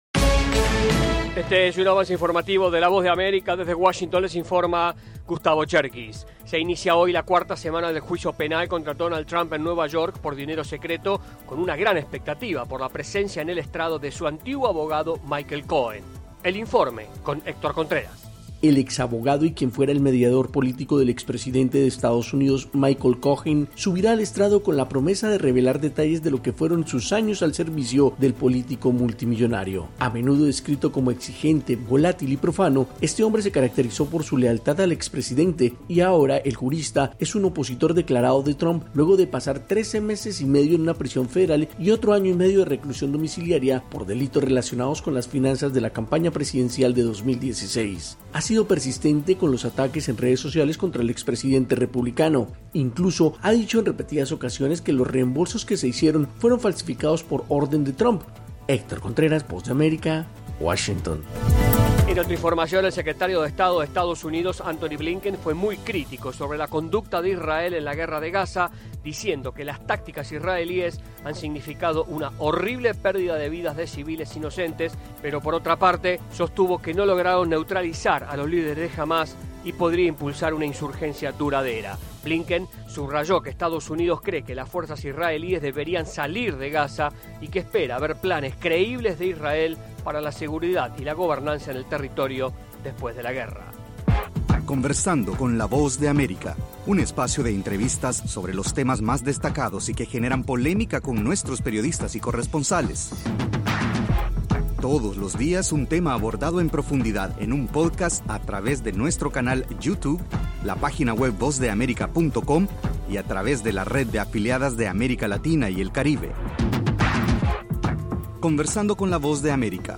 Este es un avance informativo de la Voz de América.